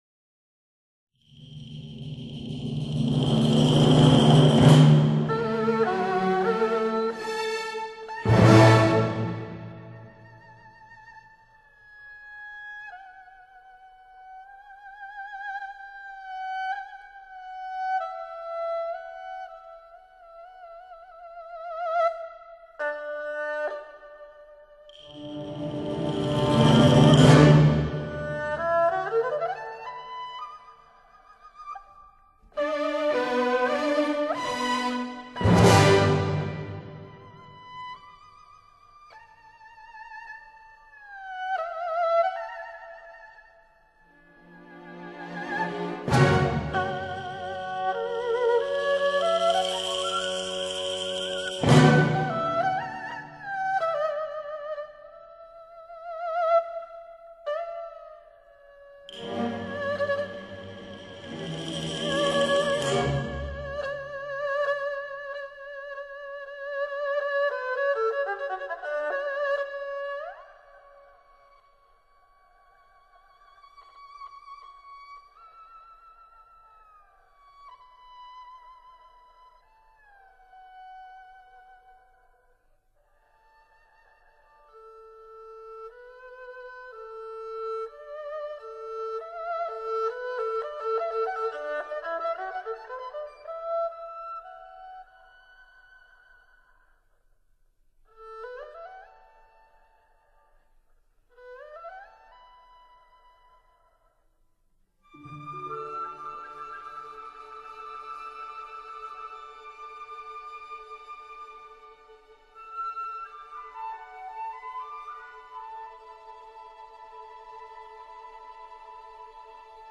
二胡演奏
本唱片被称为史上最靓声二胡天碟，动态庞大，试音必备。